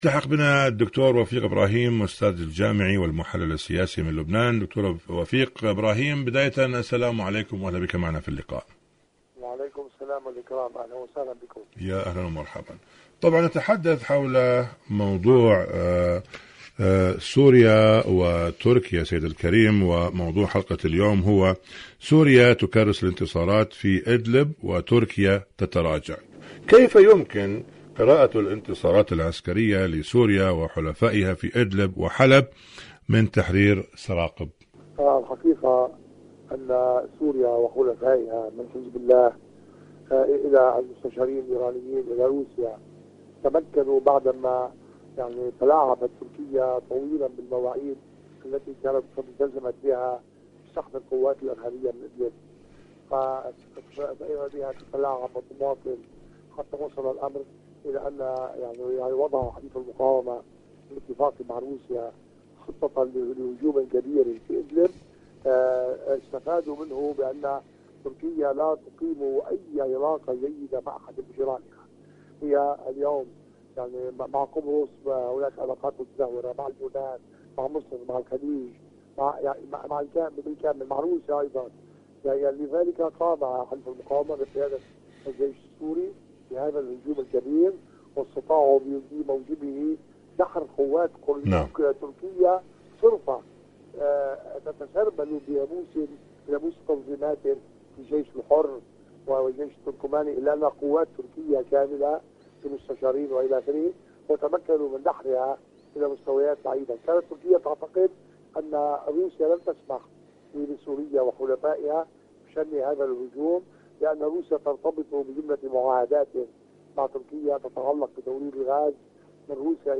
إذاعة طهران-أرض المقاومة: مقابلة إذاعية